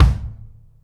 Index of /90_sSampleCDs/E-MU Producer Series Vol. 8 – Platinum Phatt (CD 1)/PlatinumPhattCD1/Live Kicks
KICK HARD1AL.wav